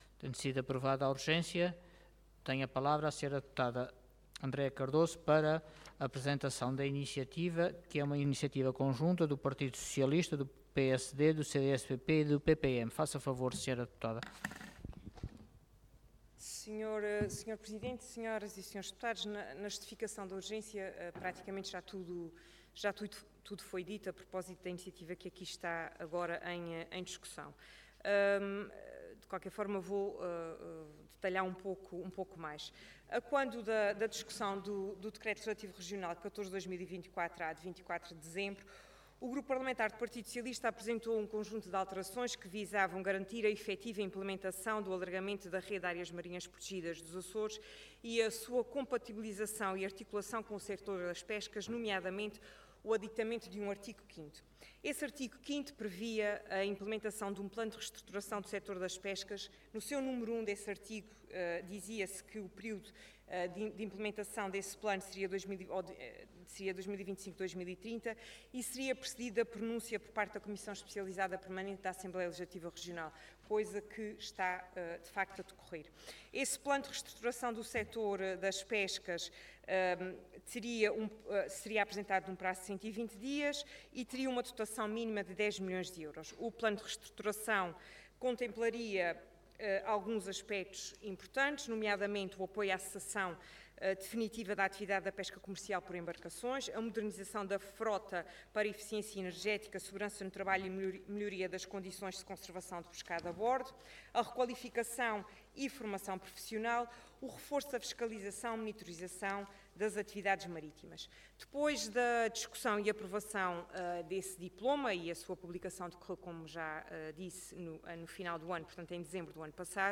Intervenção
Orador Andreia Costa Cargo Deputada Entidade Autores Vários